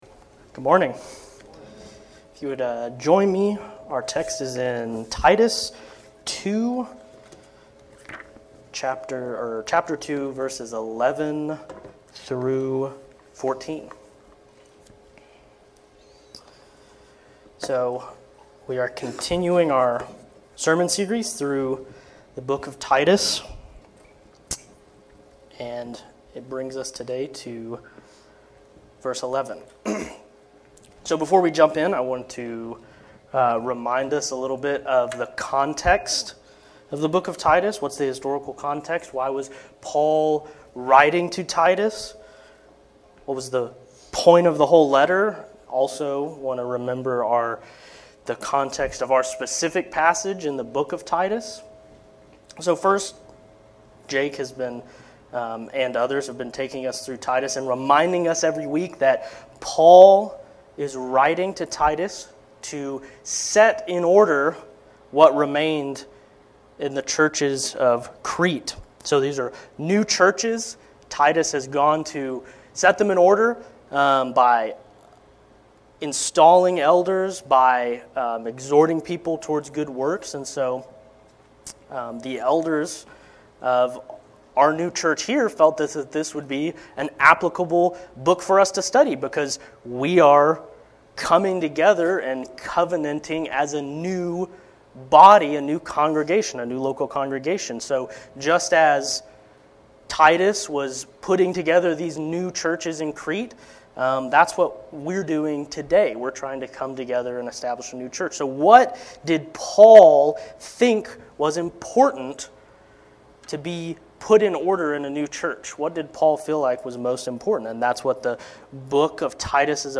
Sermons | Grace Reformed Baptist Church of North Texas